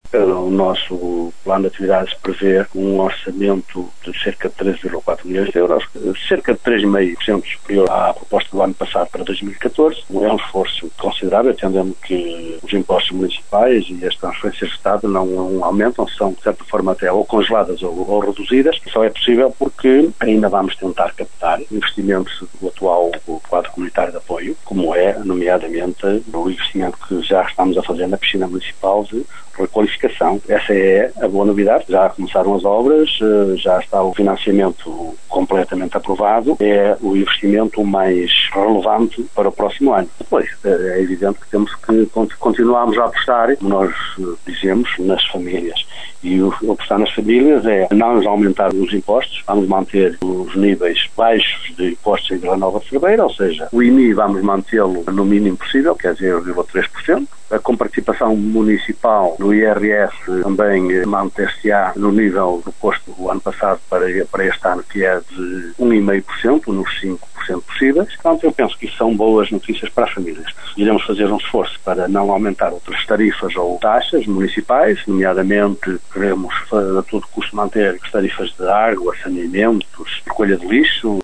Após a aprovação pelo executivo camarário, o autarca Fernando Nogueira está confiante no voto favorável da Assembleia Municipal a um Orçamento que, diz o autarca, vai favorecer as famílias.
cerveira-apresentaçao-orçamento-am-fernando-nogueira.mp3